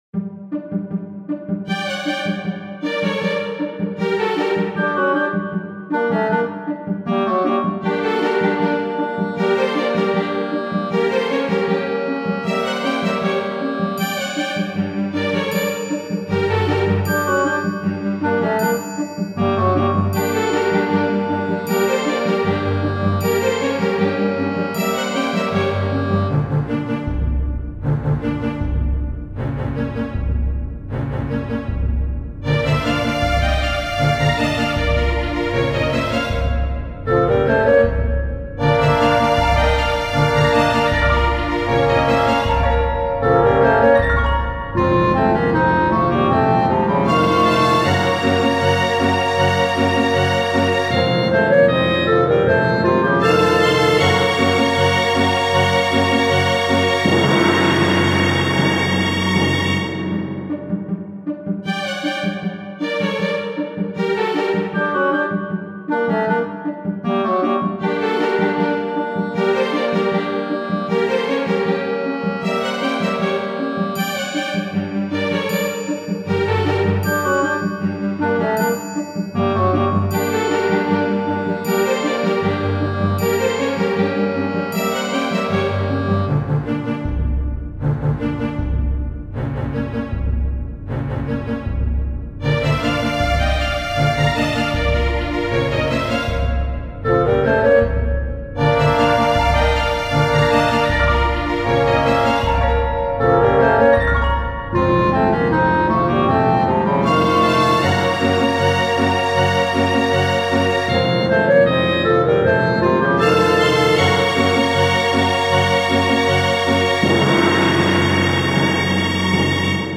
イメージ：洞窟 緊迫   カテゴリ：RPG−外・ダンジョン